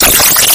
electrohaus/electroclash